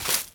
Broom Sweeping
sweeping_broom_leaves_stones_06.wav